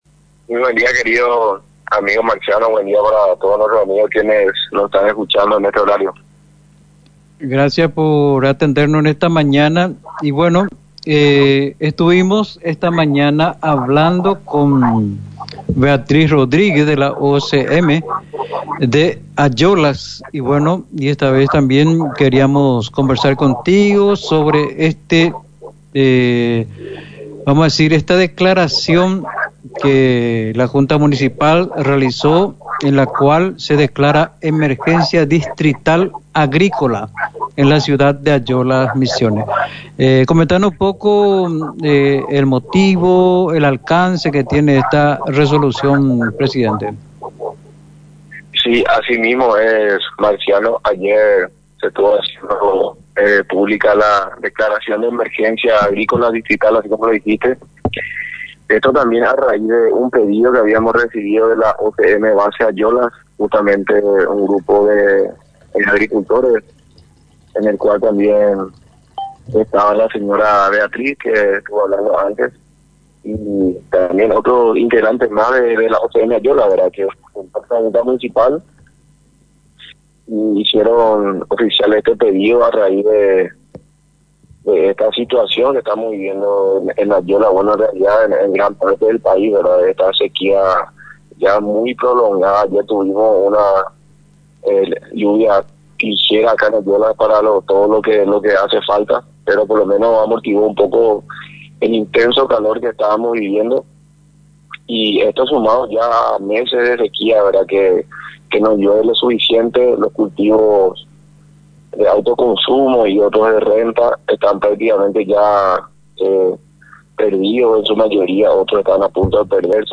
El presidente de la Junta Municipal, Denis Flores, relató en diálogo con Misión FM las principales preocupaciones que se argumentaron para la presente declaración.